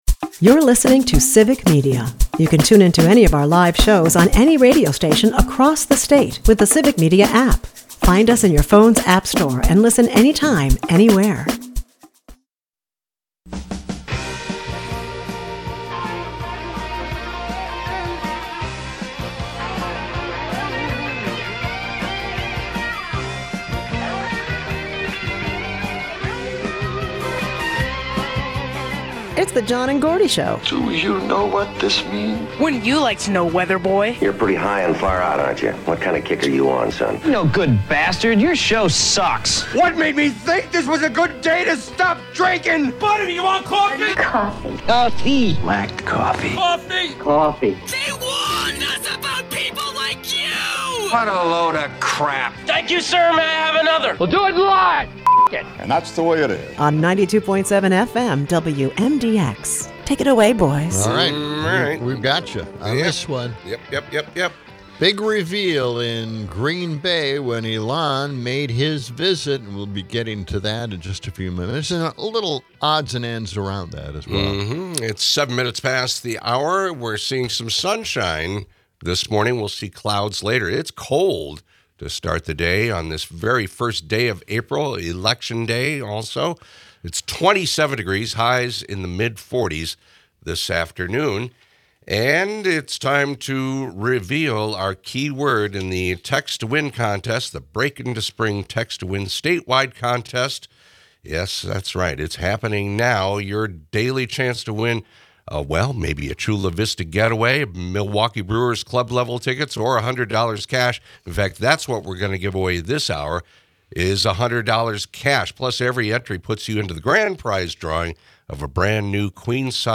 We talk more about Elon Musk, with some of the despicable and detestable clips of him from Green Bay. Wrapping up today, we talk more about the election - and some of Bumbling Brad Schimel's most unpopular moves from his time as Attorney General.